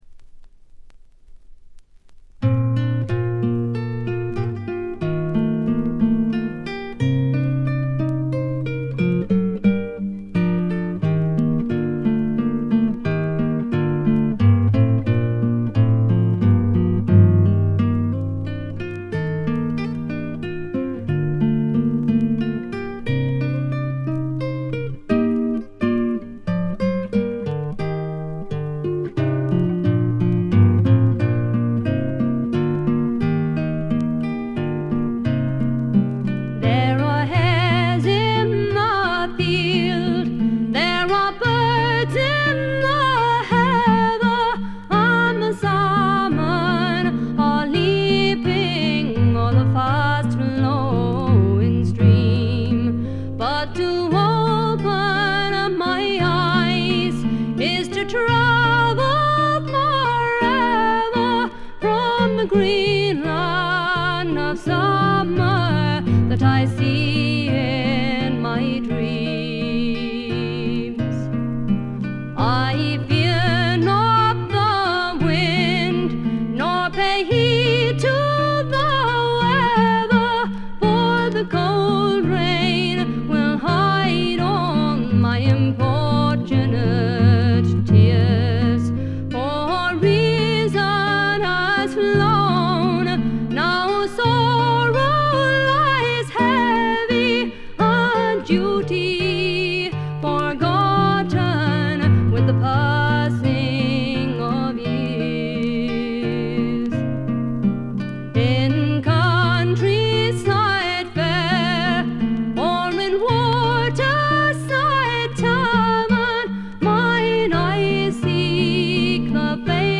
ホーム レコード：英国 フォーク / トラッド
わずかなバックグラウンドノイズ、チリプチ程度。
試聴曲は現品からの取り込み音源です。